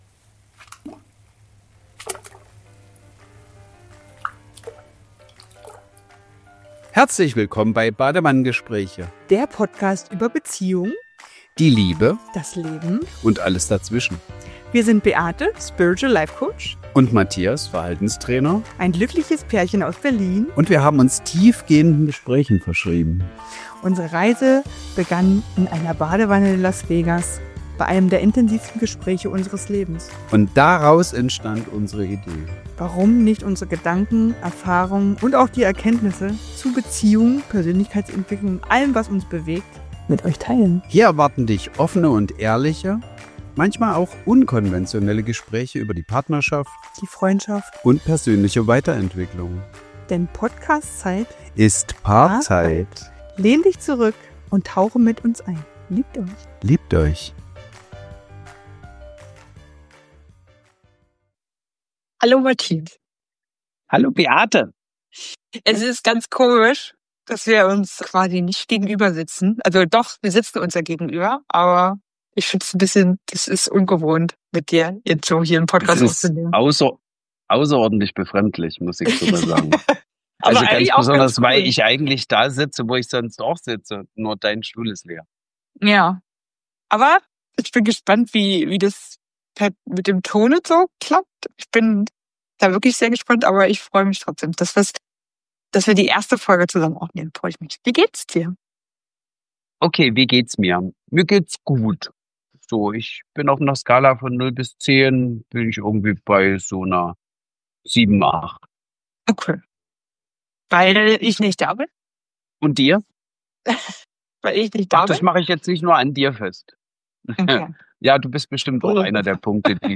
Wir starten in die neue Staffel – diesmal getrennt voneinander, aber innerlich ganz verbunden.
Zwischen Spanien und Deutschland sprechen wir über Nähe, Veränderung, Enttäuschung und darüber, was es für uns bedeutet, ein erfülltes Leben zu führen.